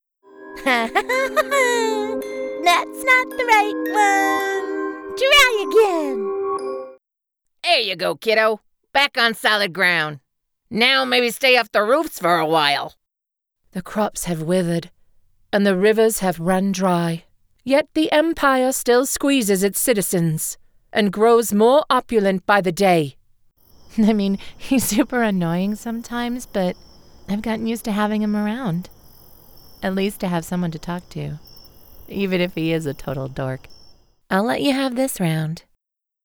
North American Female Voiceactor
Video Game/Animation Demo
I take pride in crafting a voice recording that suits your needs using my professional home studio.